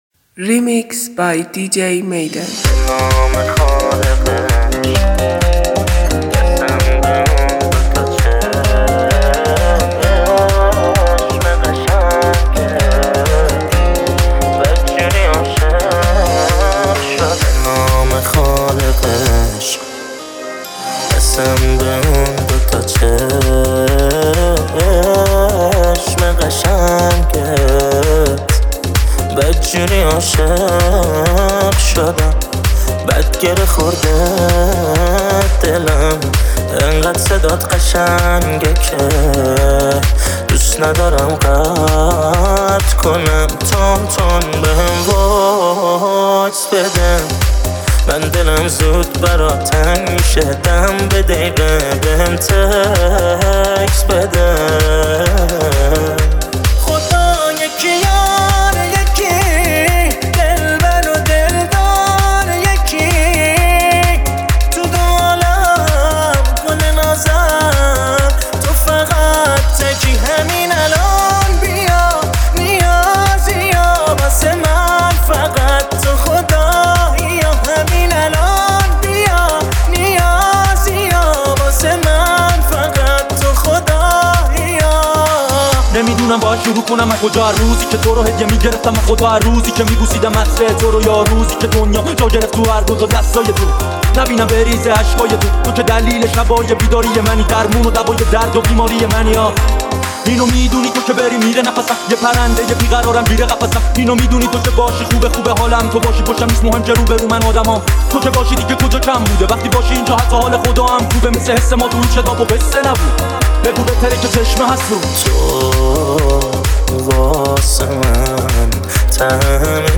دانلود ریمیکس جدید